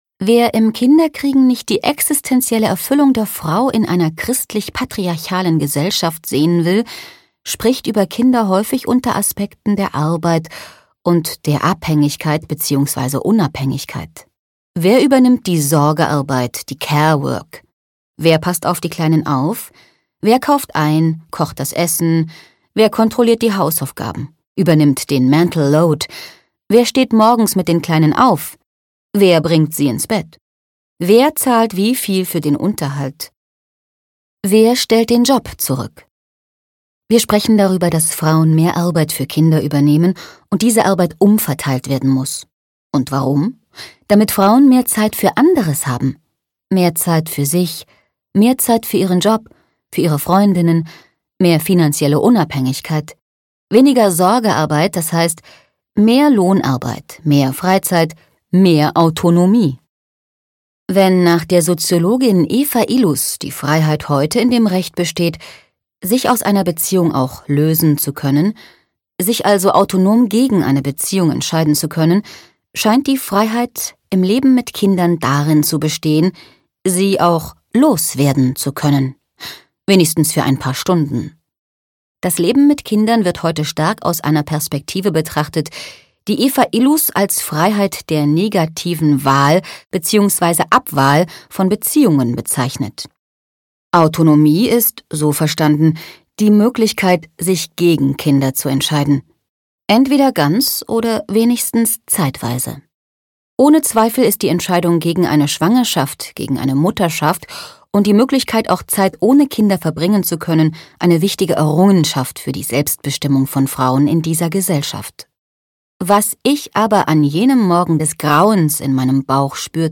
Werbung - BerlinerKurier